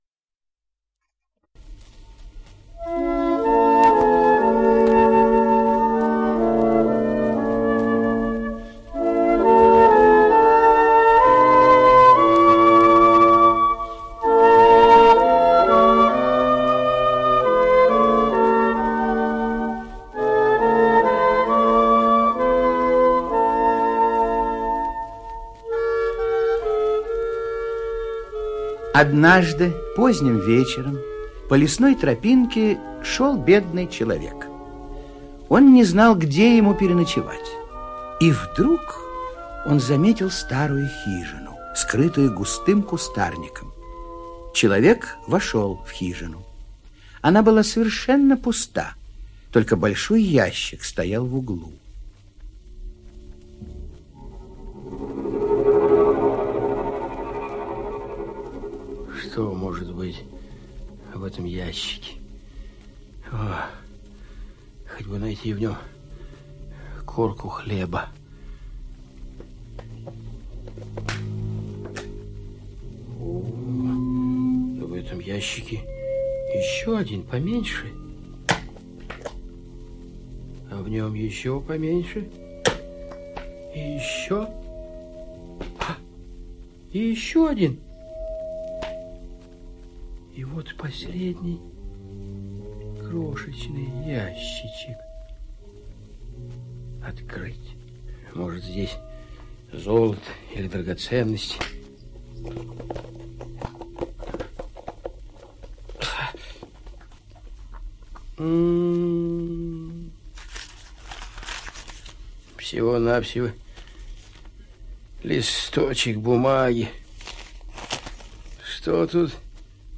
Явись, мой слуга - шведская аудиосказка - слушать онлайн